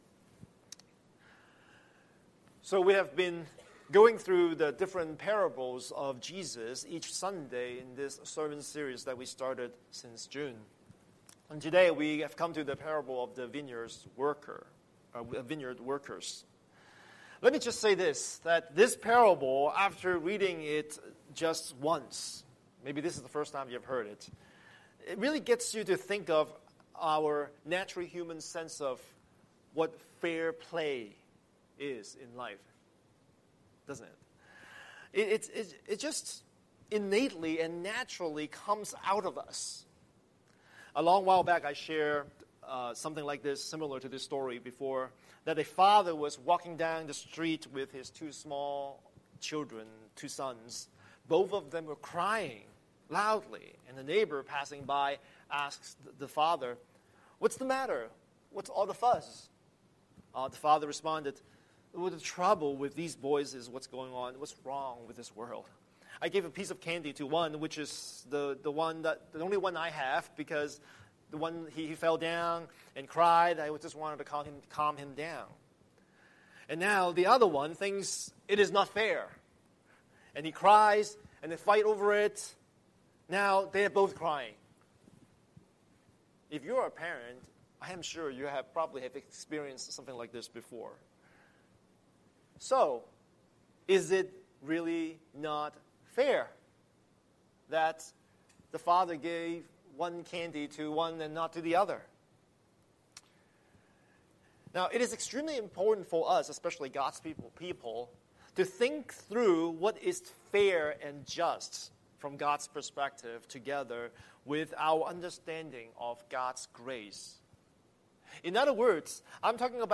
Scripture: Matthew 20:1-16 Series: Sunday Sermon